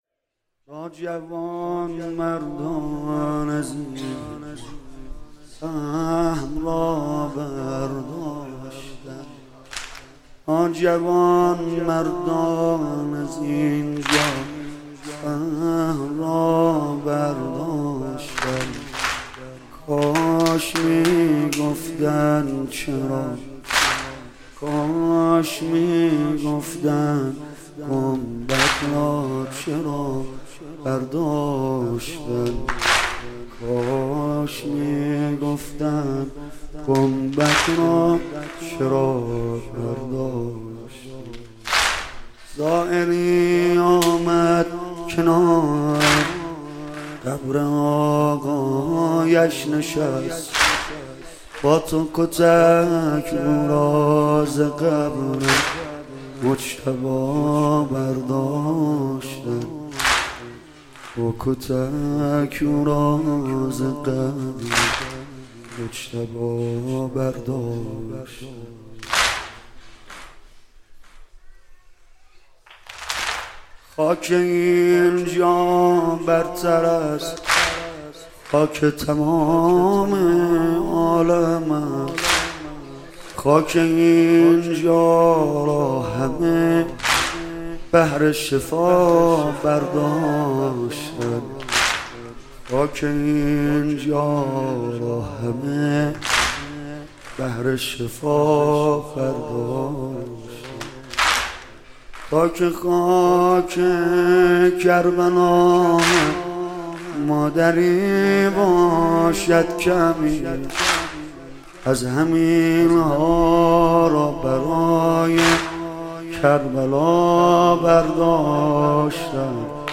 روضه امام صادق علیه السلام